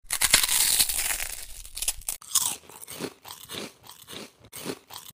When your trying to listen for enemies and all you hear is your dead teammate eating